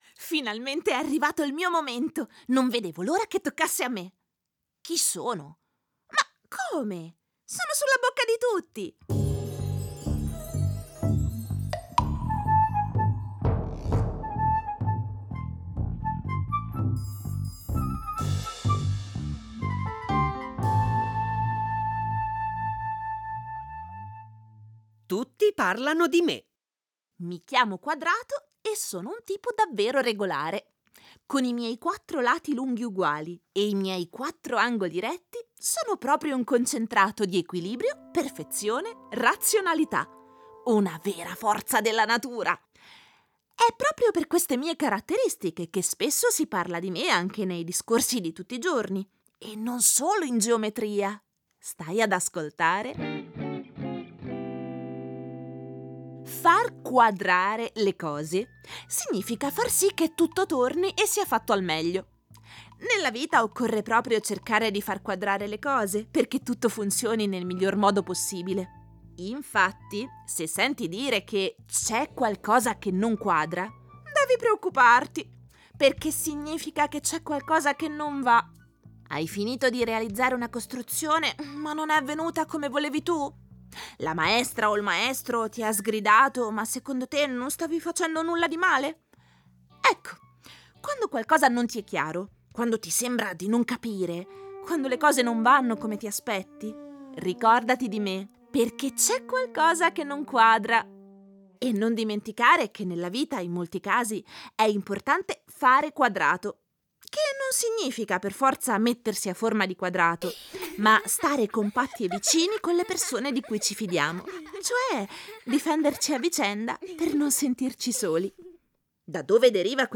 Ascoltando le storie, ci avvicineremo alla geometria in modo giocoso e divertente.